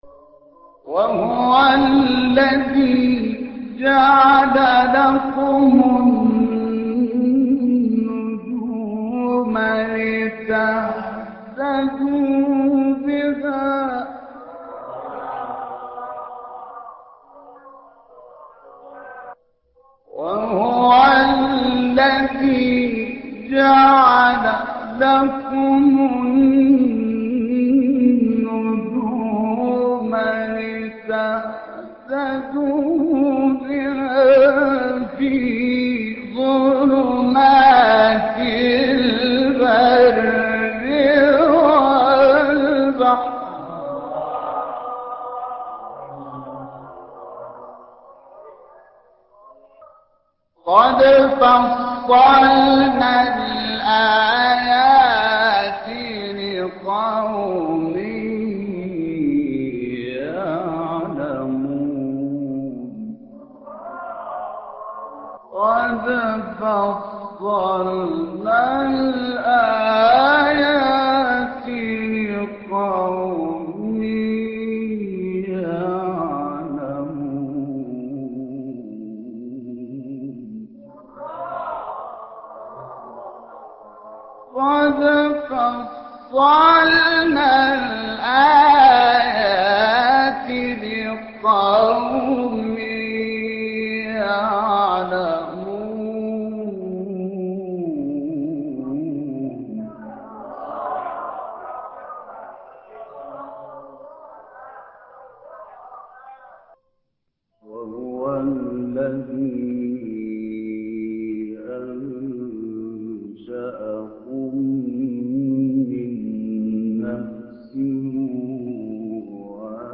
سورة الانعام ـ الشحات انور ـ مقام العجم - لحفظ الملف في مجلد خاص اضغط بالزر الأيمن هنا ثم اختر (حفظ الهدف باسم - Save Target As) واختر المكان المناسب